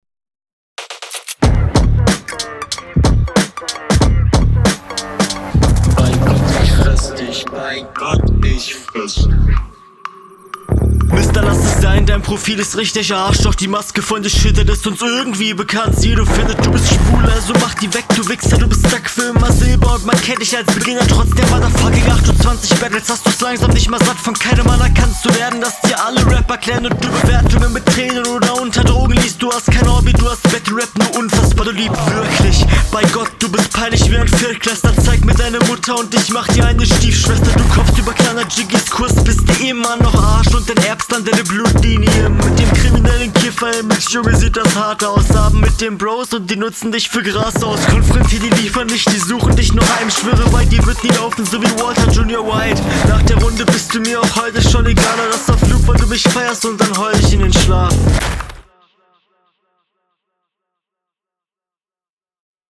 Textlich zwischen irrelevant und ganz gut aber dafür flowlich und vom Mixing her super.
Der Beat ist ultrageil und du kommst sehr strong darauf.